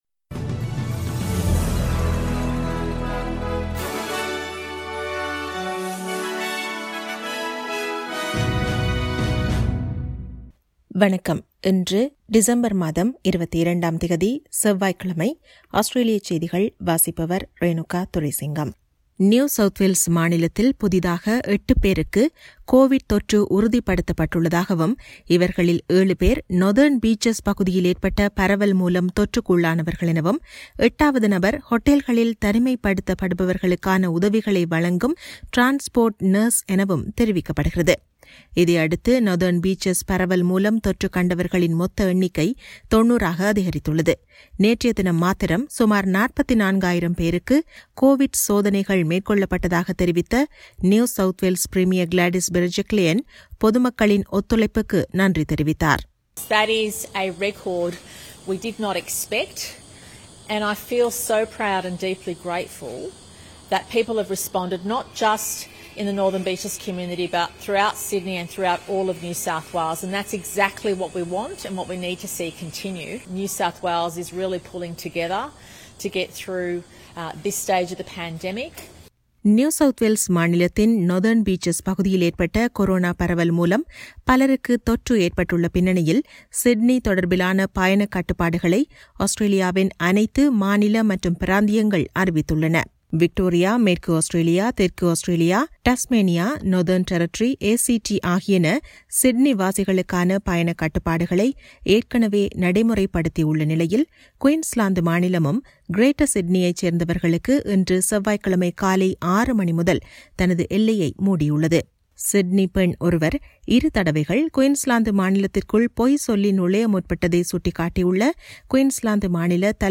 Australian news bulletin for Tuesday 22 December 2020.